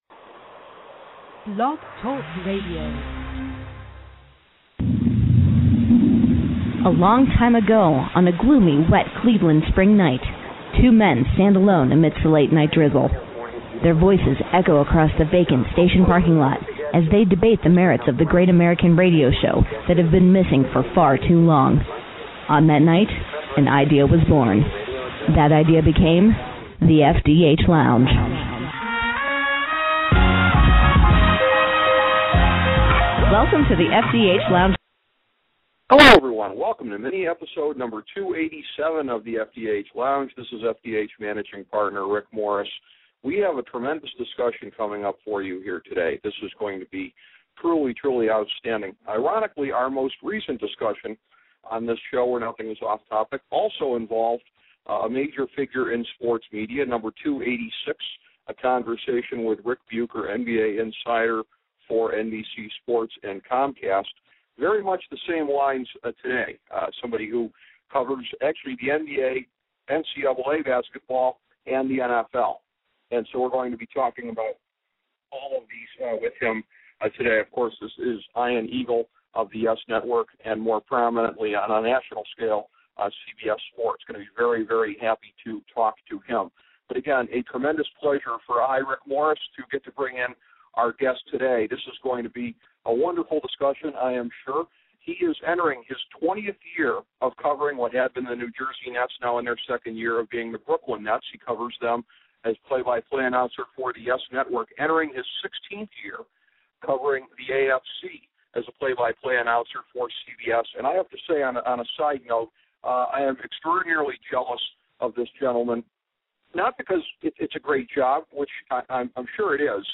A conversation with Ian Eagle
In another appearance on the Sportsology channel, The FDH Lounge sits down with CBS Sports and YES Network broadcaster Ian Eagle.